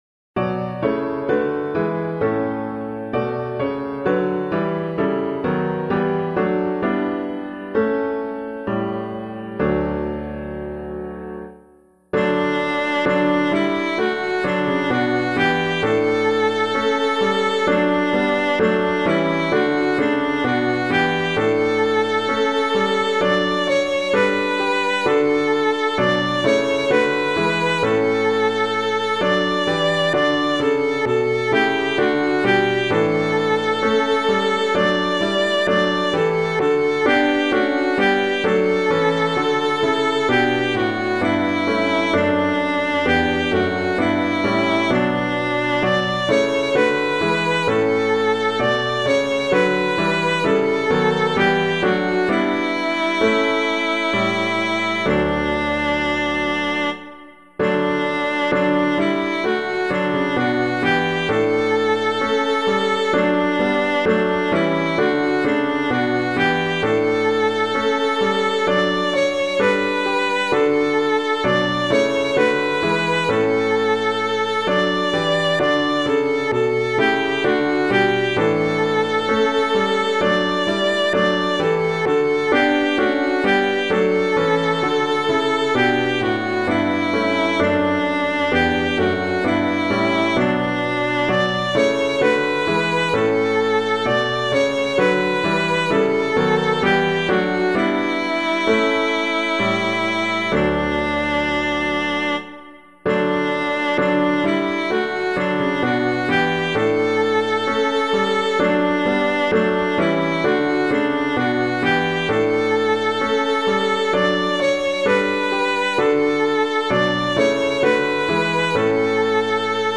Bring All Ye Dear-Bought Nations Bring [Blount - LASST UNS ERFREUEN] - piano.mp3